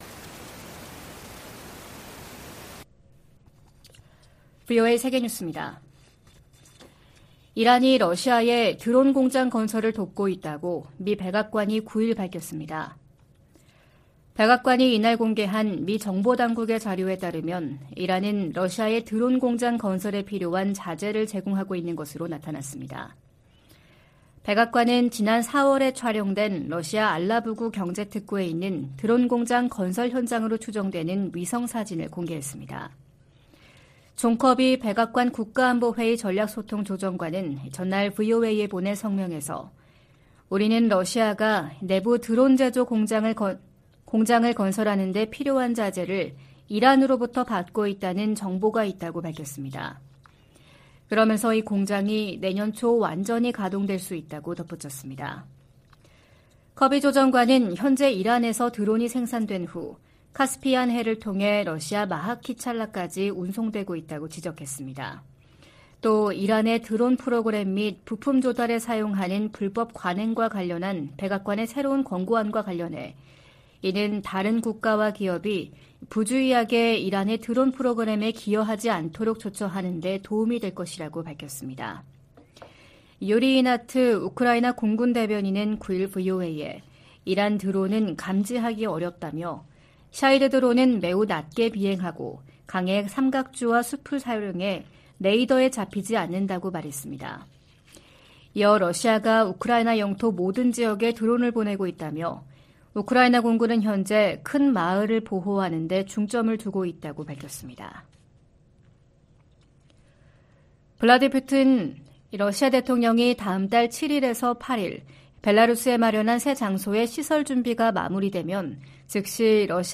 VOA 한국어 '출발 뉴스 쇼', 2023년 6월 10일 방송입니다. 미 국무부가 한국의 새 국가안보전략을 환영한다고 밝혔습니다.